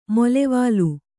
♪ molevālu